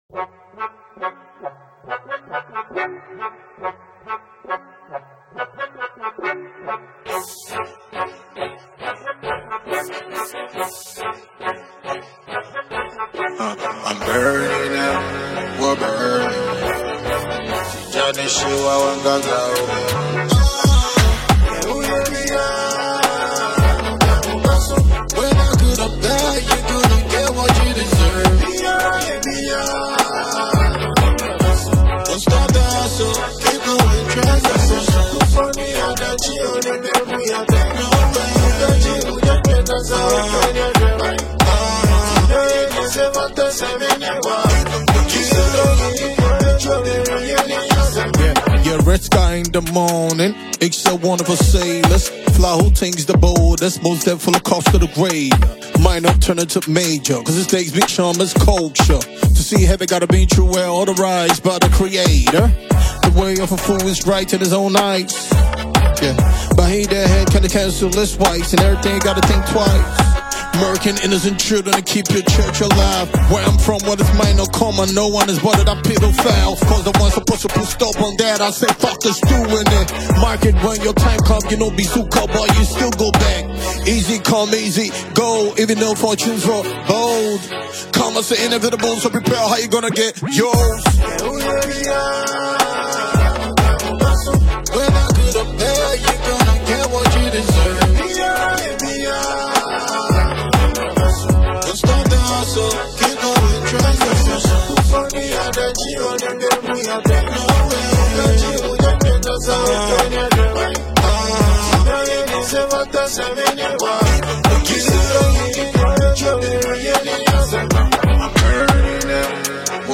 Ghana Music
is a mid-tempo Afro-Hip-Hop record